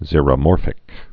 (zîrə-môrfĭk)